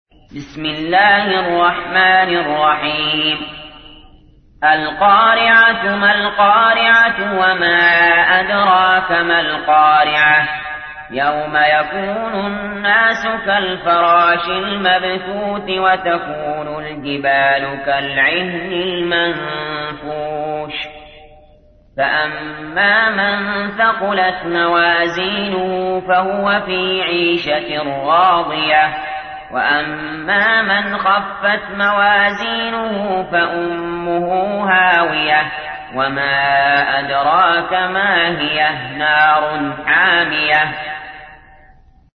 تحميل : 101. سورة القارعة / القارئ علي جابر / القرآن الكريم / موقع يا حسين